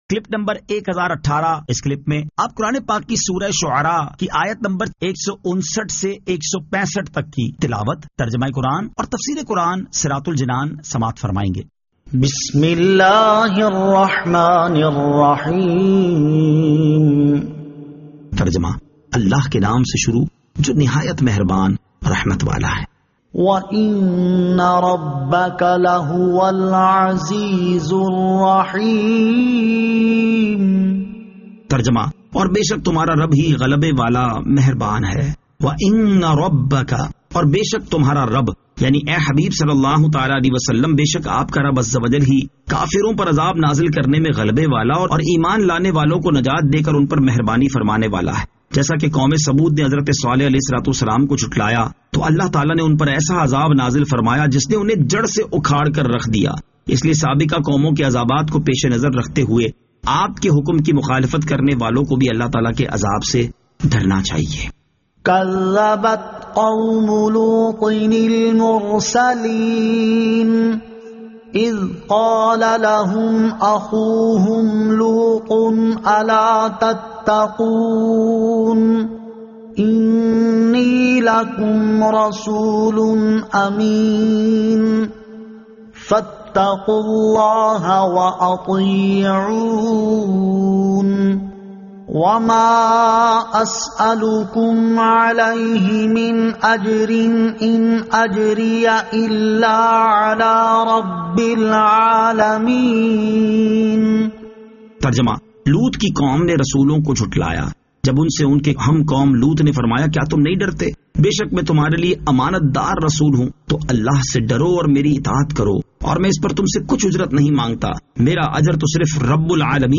Surah Ash-Shu'ara 159 To 165 Tilawat , Tarjama , Tafseer